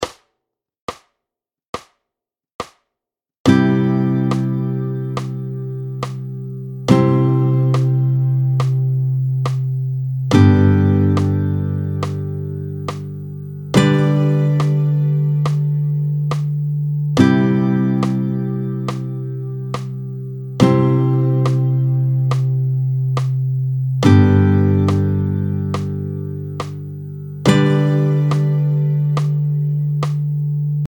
11-03 Barrés de Do et de Sol, tempo 70
Voyons cela avec DO (mesures 1 et 2), avec deux positions en barré, réalisées à la 3ème et à la 8ème case de la guitare.